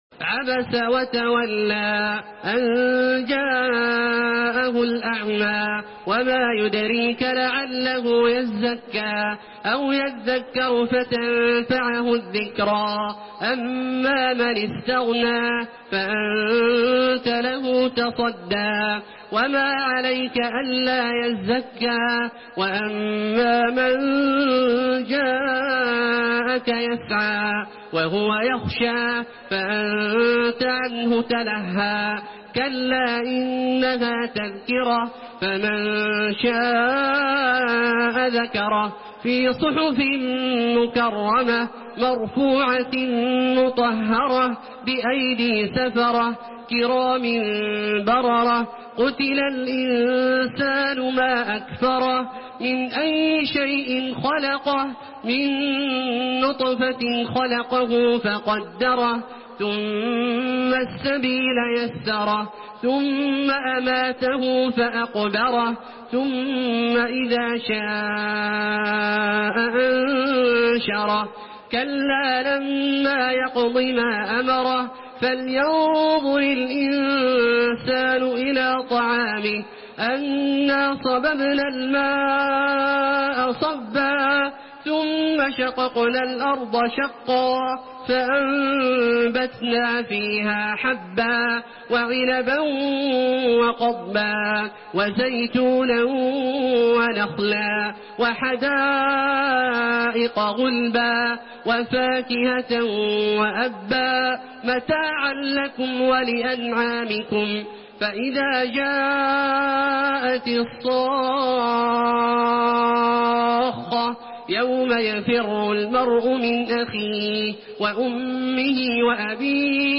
Surah ‘আবাসা MP3 by Makkah Taraweeh 1432 in Hafs An Asim narration.